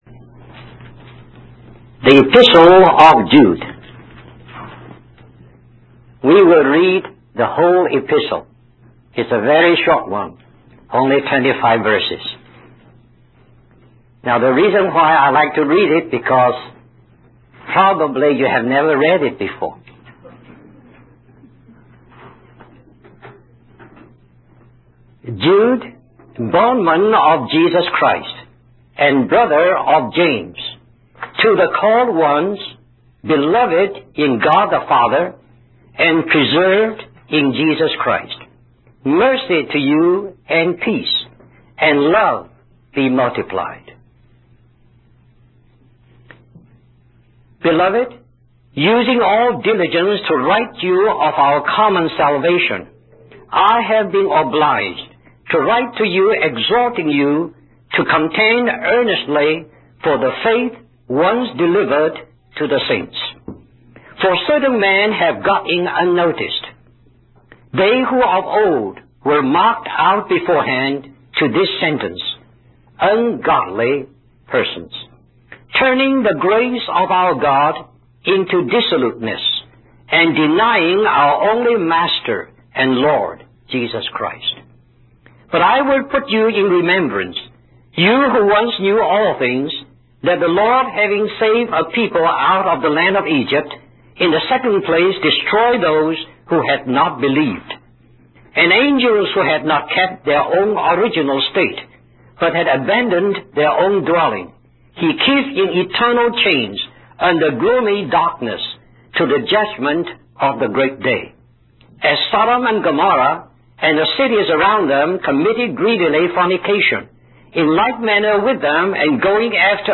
In this sermon, the preacher emphasizes the seriousness of falling away from the faith that was delivered to the saints.